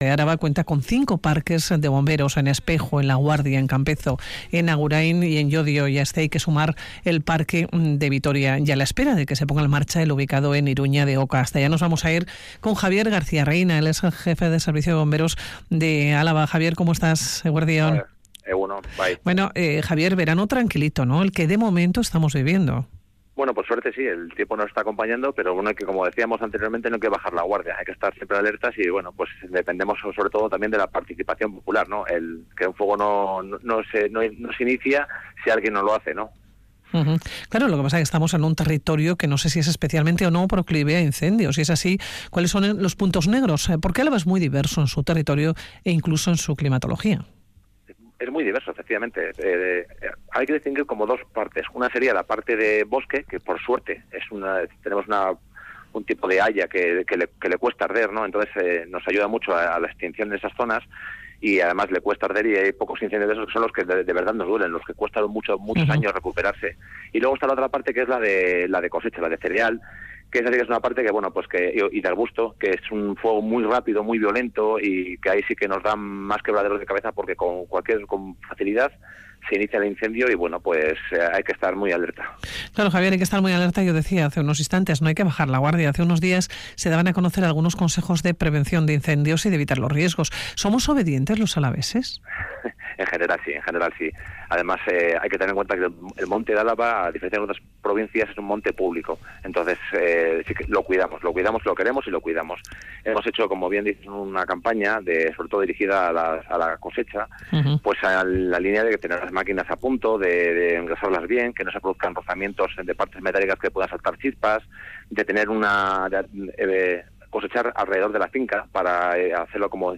Audio: Radio Vitoria| Hablamos con bomberos y conocemos detalles sobre algunos de los incencios del pasado año en Araba, fueron trece los contabilizados.